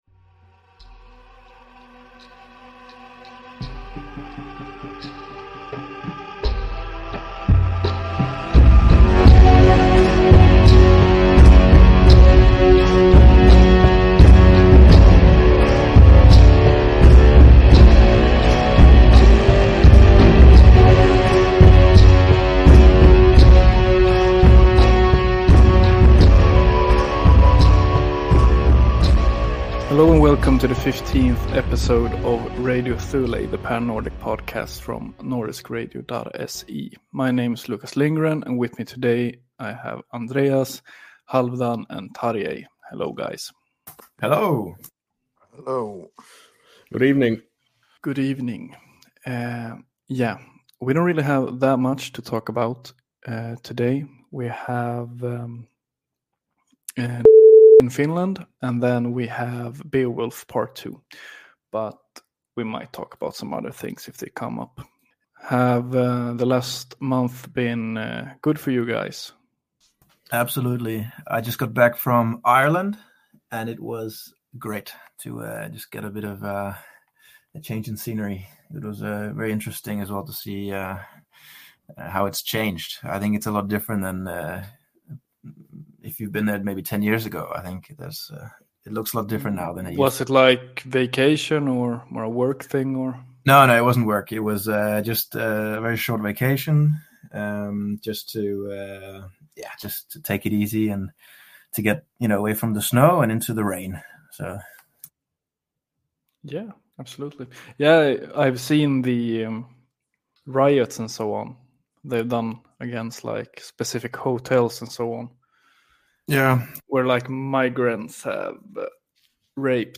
The latest episode of Radio Thule, with hosts representing most of the Nordic nations.